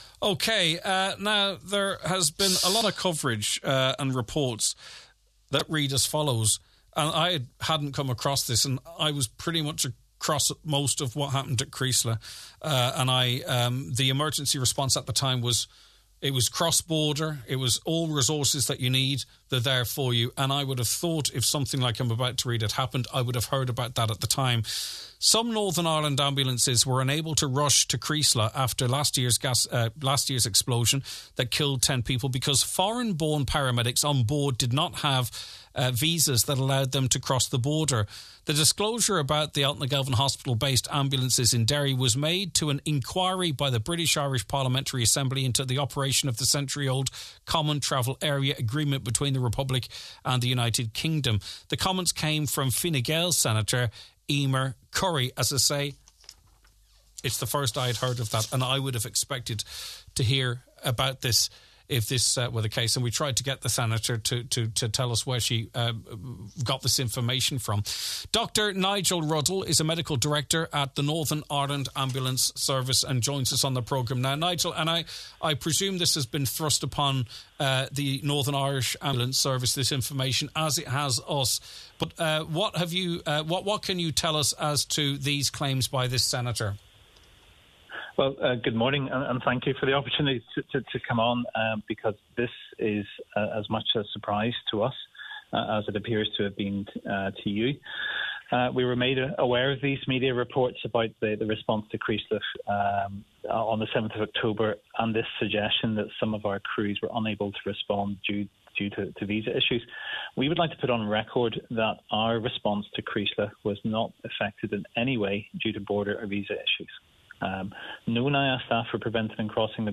on this morning’s Nine ’til Noon Show: